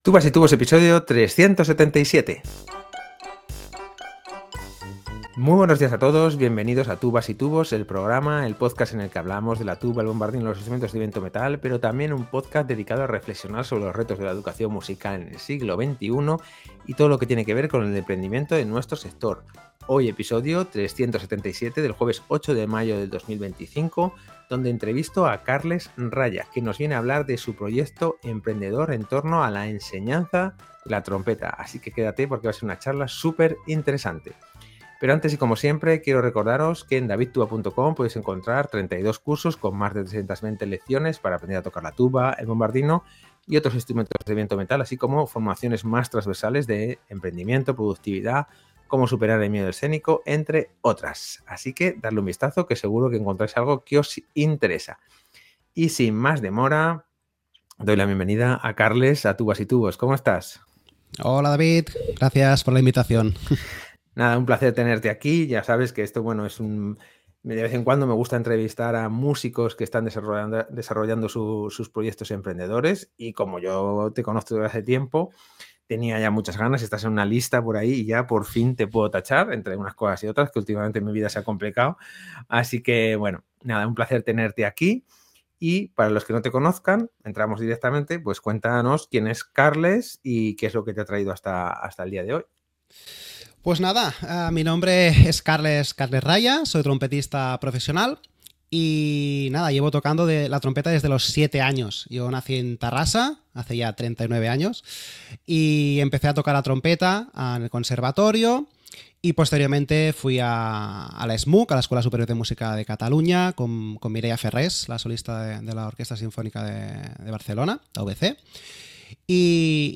entrevisto